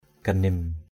/ɡ͡ɣa˨˩-nɪm˨˩/ (cv.) janim jn[ /ʥa˨˩-nɪm˨˩/ (d.) ranh, rào rẫy. ganim apuh gn[ ap~H ranh rẫy. jrah apuh jem ganim jH ap~H j# gn[ phát rẫy đắp ranh.